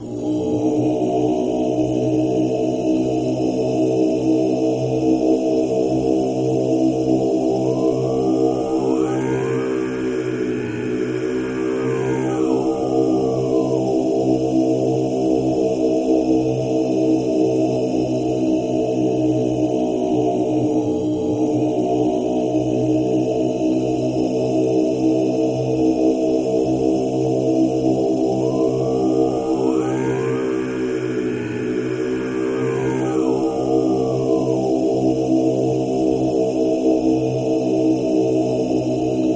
Monk Voices Low Chanting, Eerie